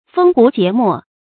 封胡羯末 fēng hú jié mò
封胡羯末发音
成语注音ㄈㄥ ㄏㄨˊ ㄐㄧㄝ ˊ ㄇㄛˋ